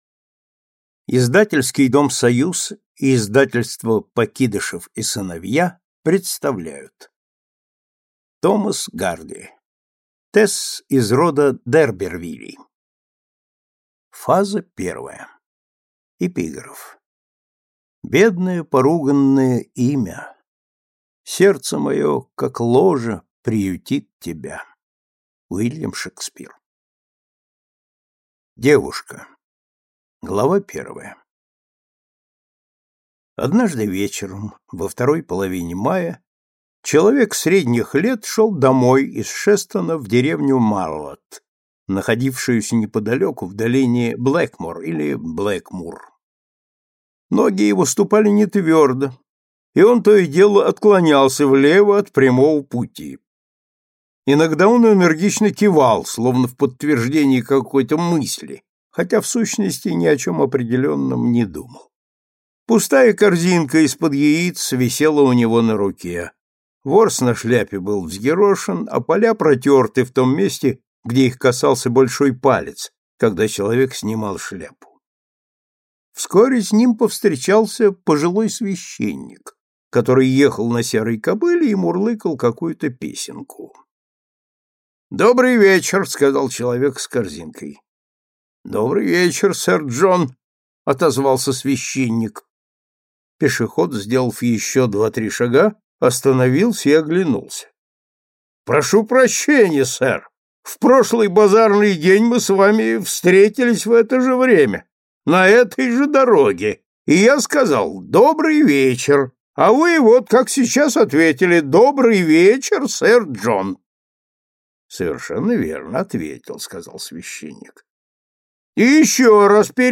Аудиокнига Тэсс из рода д´Эрбервиллей | Библиотека аудиокниг